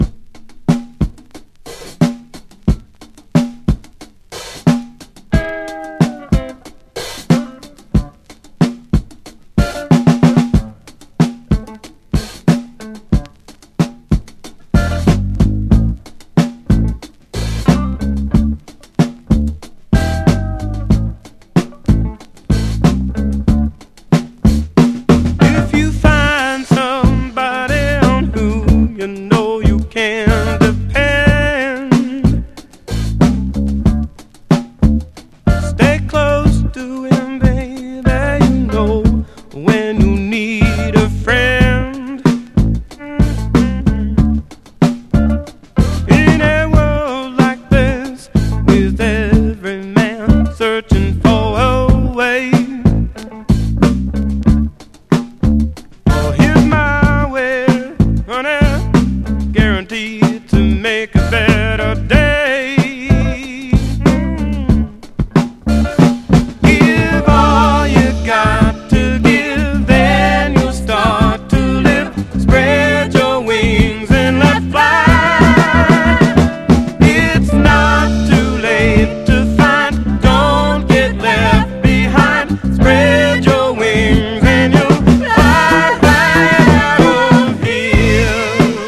ROCK / 70'S / 60'S
だるいハンド・クラップにひずみまくったギターがカッチョ良すぎる